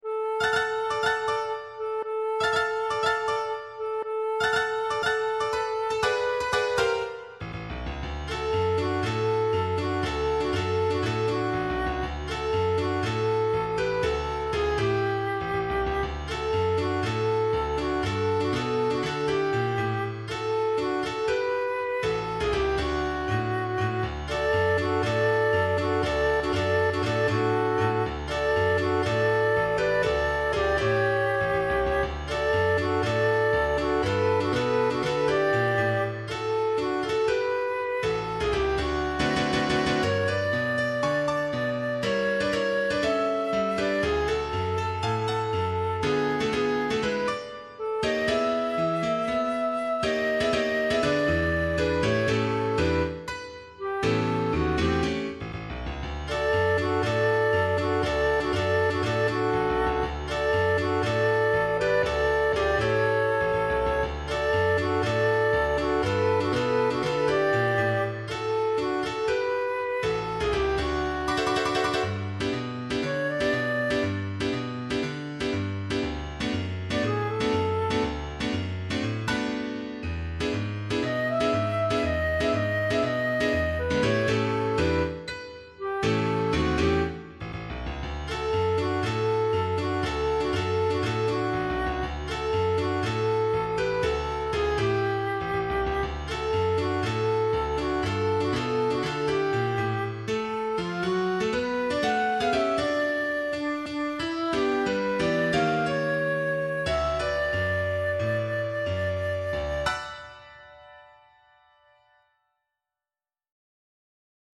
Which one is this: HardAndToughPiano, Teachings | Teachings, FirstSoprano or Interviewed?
FirstSoprano